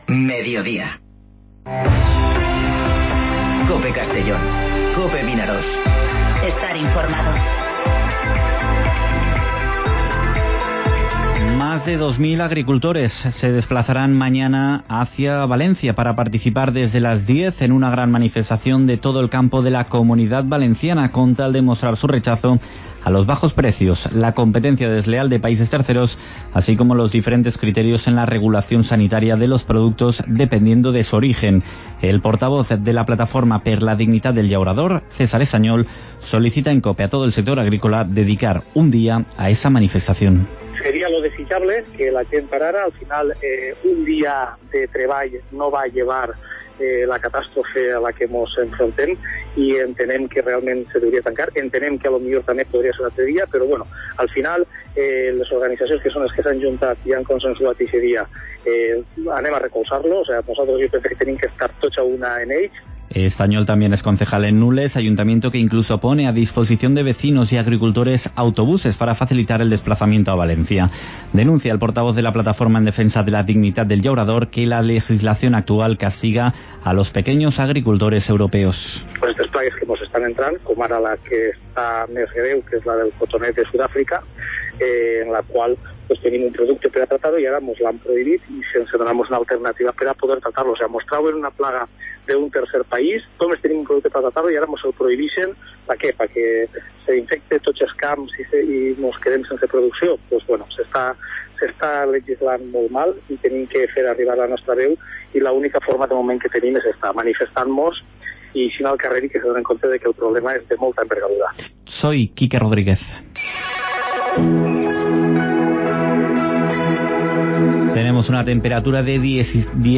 Informativo Mediodía COPE en Castellón (13/02/2020)